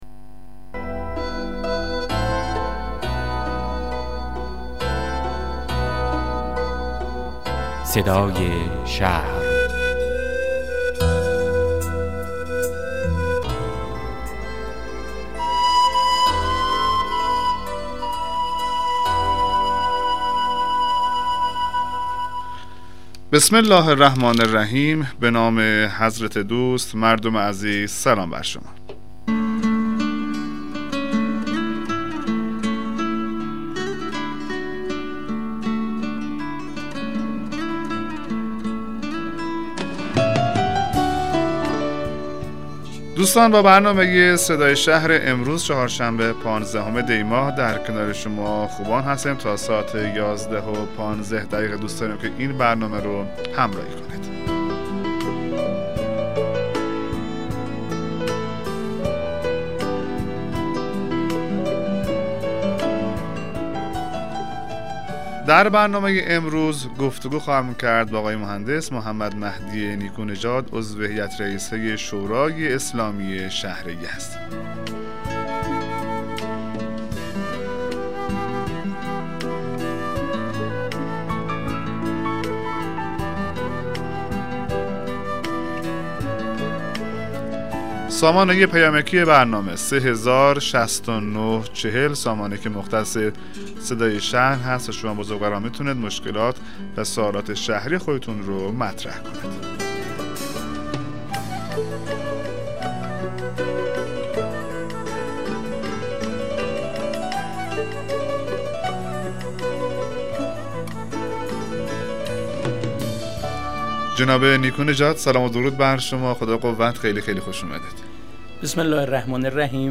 مصاحبه رادیویی برنامه صدای شهر با حضور محمدمهدی پارساییان عضو شورای اسلامی شهر یزد